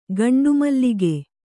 ♪ gaṇḍu mallige